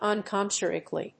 音節un・com・mer・cial 発音記号・読み方
/`ʌnkəmˈɚːʃəl(米国英語)/